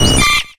CATERPIE.ogg